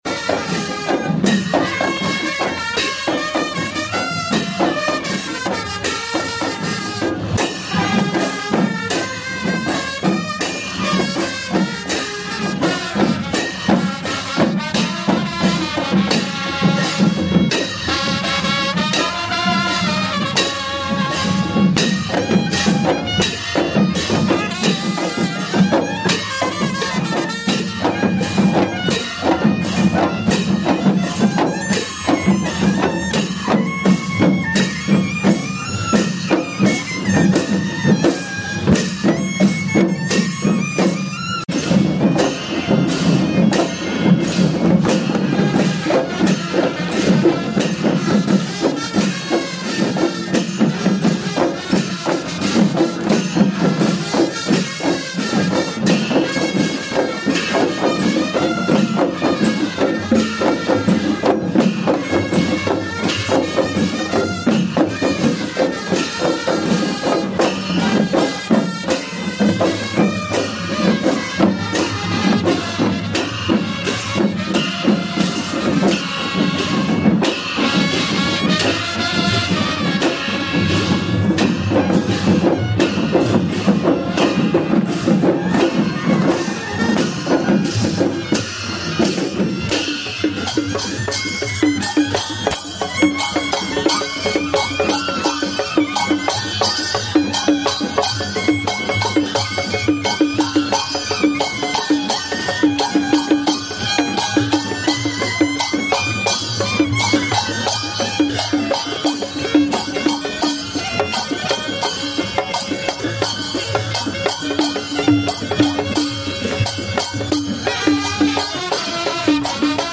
People shield themselves under umbrellas sound effects free download
People shield themselves under umbrellas while navigating through a bustling market in the early morning rain in Kathmandu. The mix of wet pavements, fresh produce stalls, and glistening streets creates a vibrant monsoon atmosphere in the city.